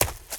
STEPS Leaves, Run 08.wav